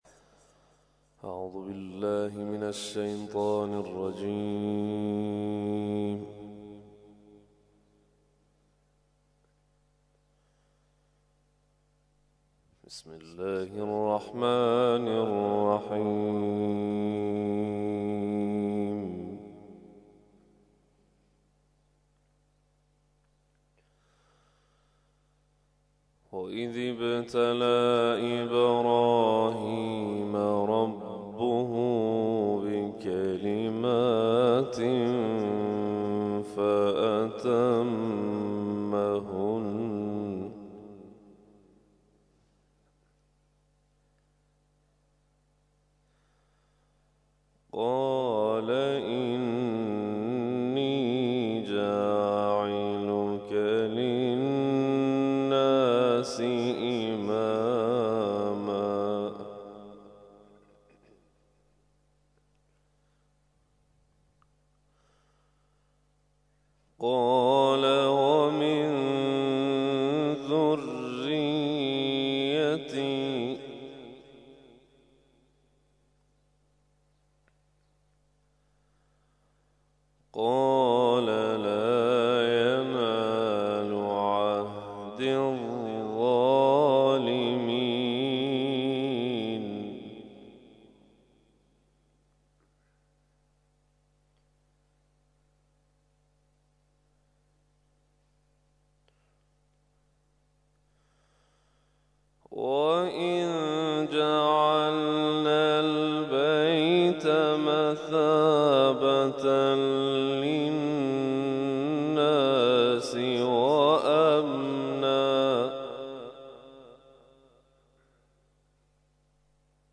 تلاوت مغرب - سوره نور آیات ( ۳۵ الی ۳۷) و سوره عصر Download